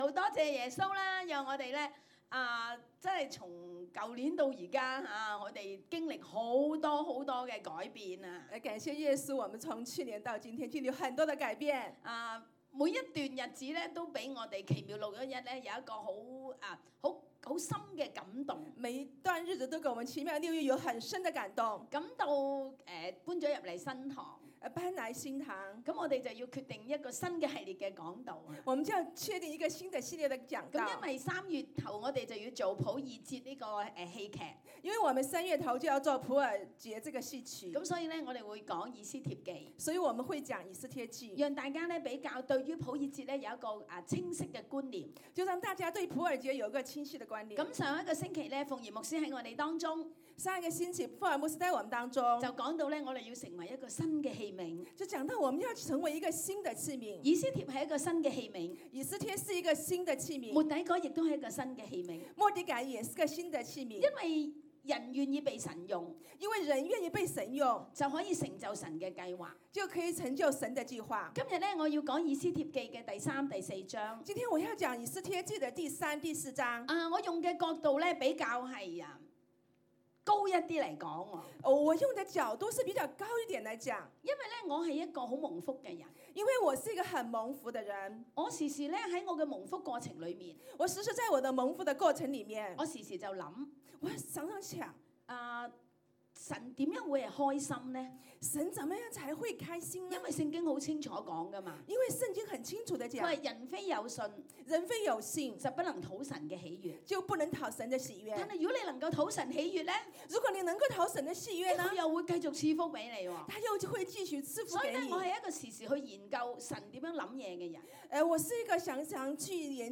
18/02/2018 講道